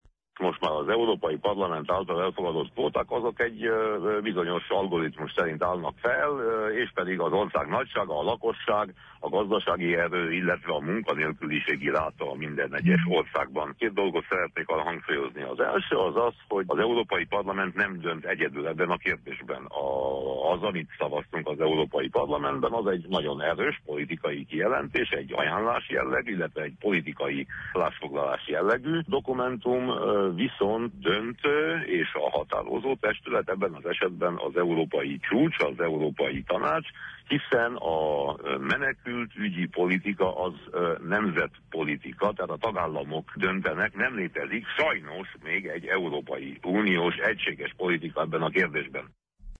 Winkler Gyula EP-képviselő a kötelező kvóta-rendszer meghatározásának feltételeiről nyilatkozott rádiónknak.